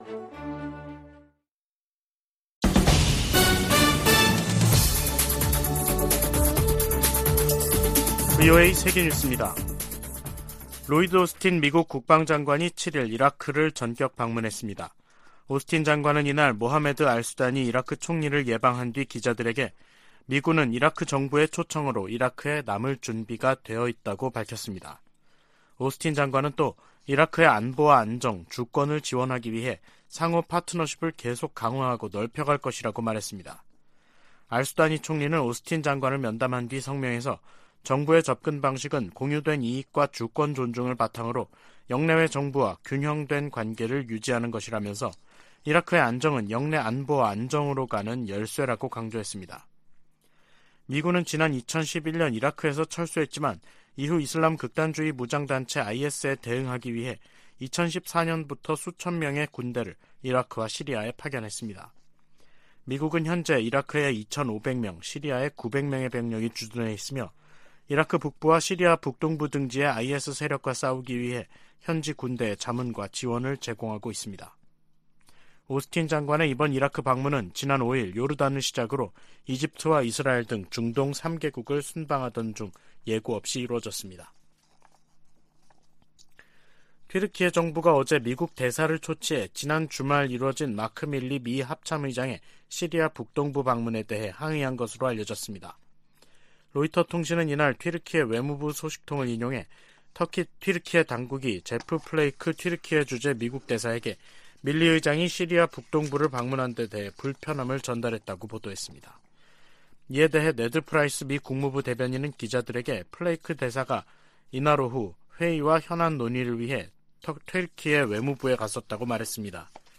VOA 한국어 간판 뉴스 프로그램 '뉴스 투데이', 2023년 3월 7일 3부 방송입니다. 북한이 이달 또는 다음달 신형 고체 대륙간탄도미사일(ICBM)이나 정찰위성을 발사할 가능성이 있다고 한국 국가정보원이 전망했습니다. 미 국무부는 강제징용 문제 해법에 대한 한일 간 합의를 환영한다는 입장을 밝혔습니다. 한국이 역사 문제 해법을 발표한 데 대해 일본도 수출규제 해제 등으로 적극 화답해야 한다고 미국 전문가들이 주문했습니다.